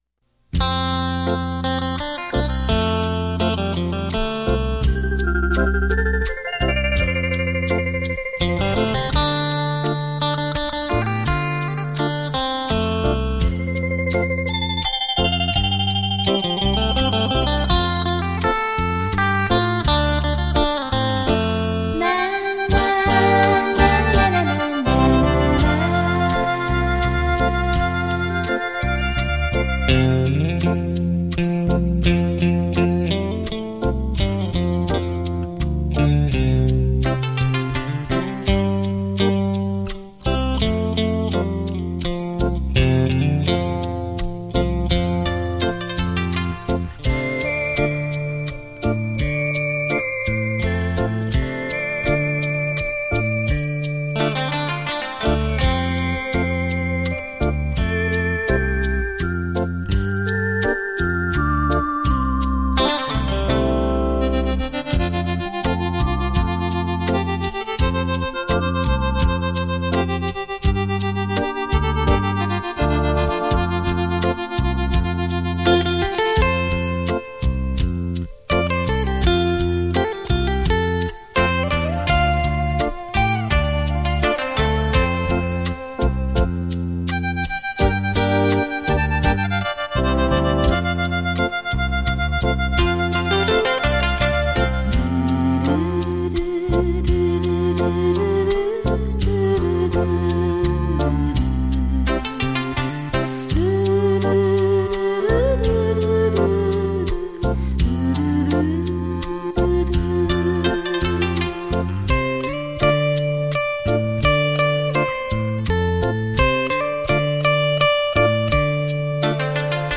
数位录音 品质保证
华尔兹